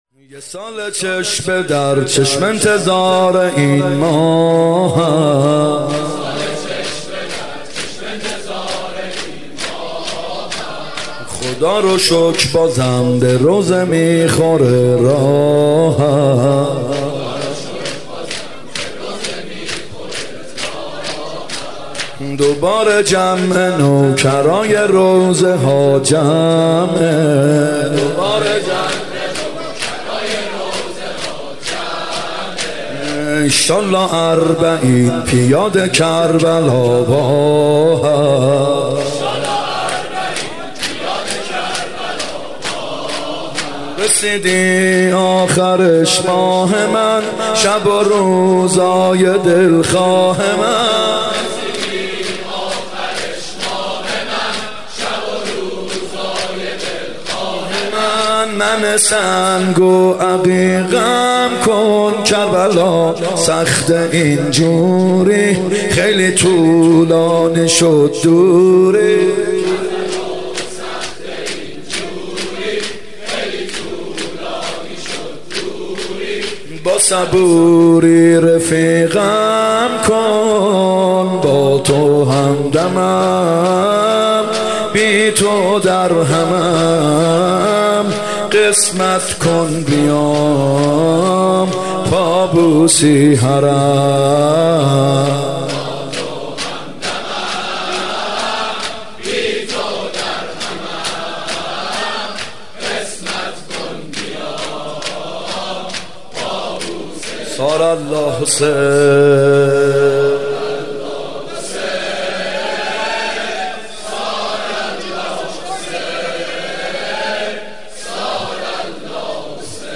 محرم 95(هیات یا مهدی عج)
محرم 95 شب هشتم شور سرود پایانی (یا نعم الامیر دستامو بگیر